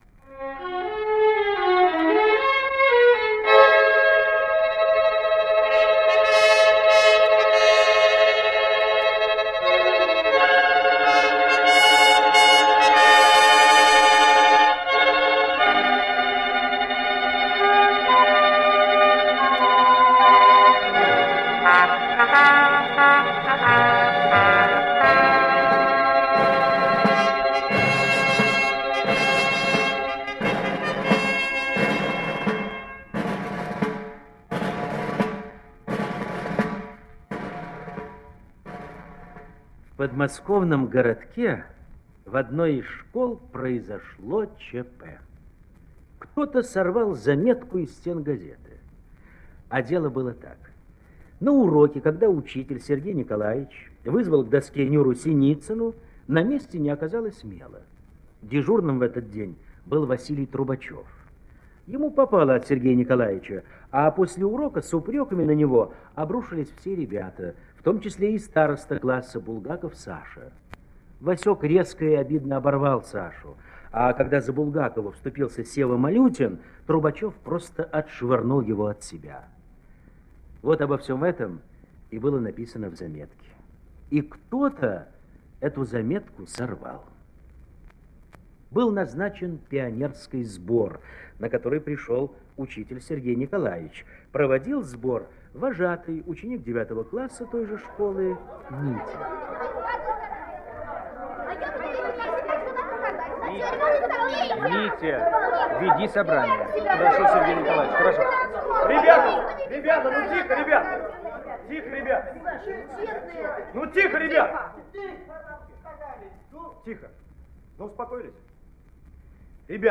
Васек Трубачев и его товарищи - аудио роман Осеевой